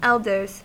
Uttal
Uttal US Ordet hittades på dessa språk: engelska Ingen översättning hittades i den valda målspråket.